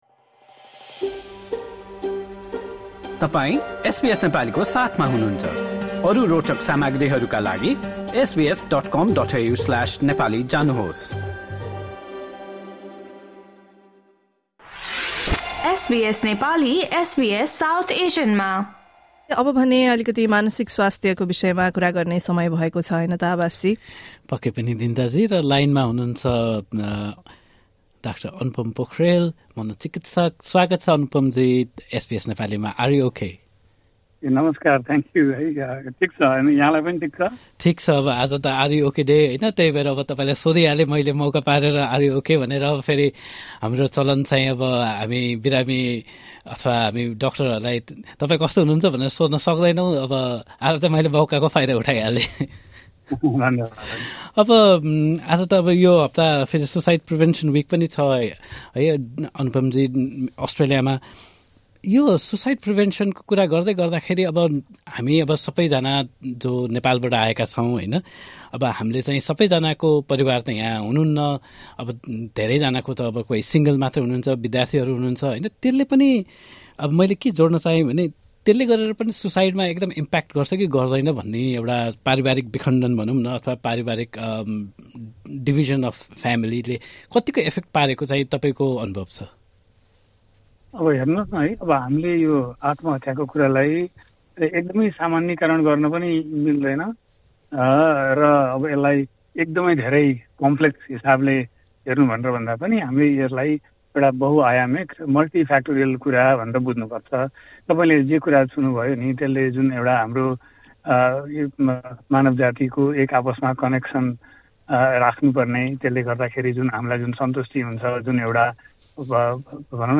एसबीएस नेपाली पोडकास्ट